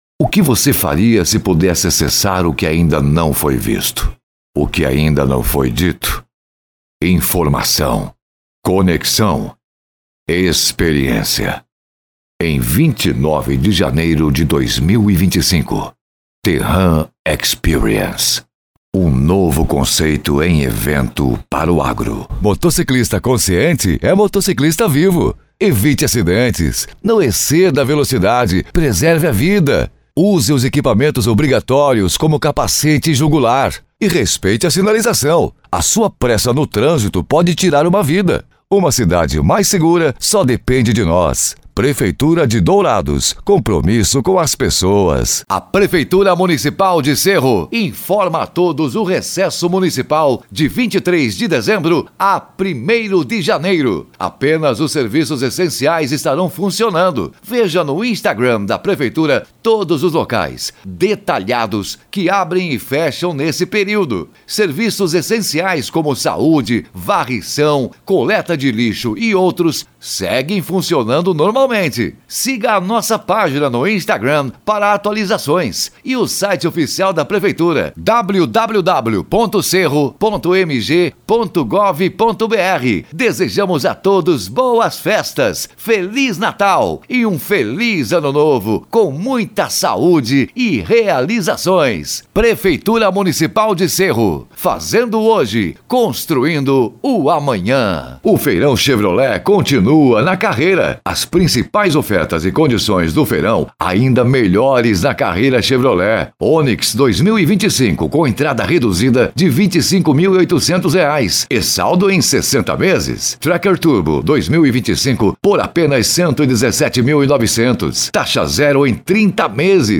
PADRÃO-INSTITUCIONAL-COLOQUIAL- IMPACTO-JOVEM-ANIMADO-PRA CIMA: